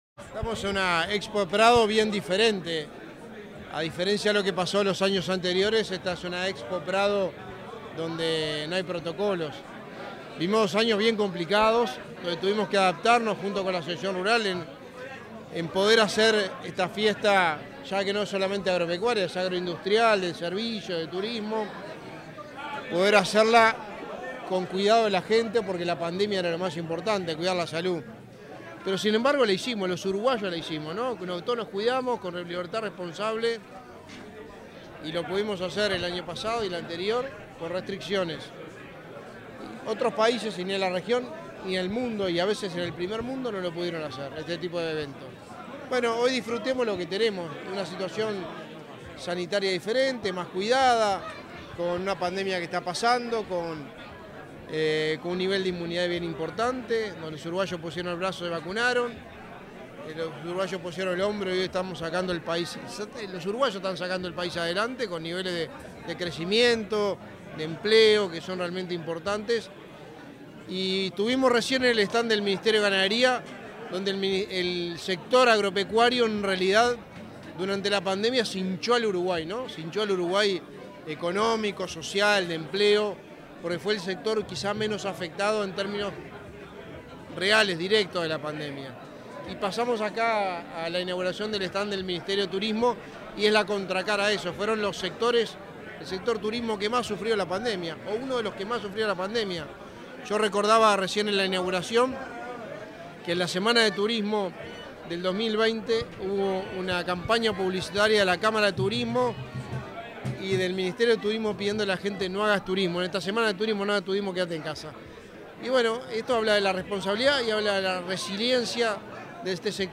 Declaraciones a la prensa del secretario de la Presidencia, Álvaro Delgado, en la Expo Prado
Declaraciones a la prensa del secretario de la Presidencia, Álvaro Delgado, en la Expo Prado 10/09/2022 Compartir Facebook X Copiar enlace WhatsApp LinkedIn Este 9 de setiembre fue inaugurado el stand del Ministerio de Turismo en la Expo Prado 2022. Tras el evento, el secretario de la Presidencia, Álvaro Delgado, realizó declaraciones a la prensa.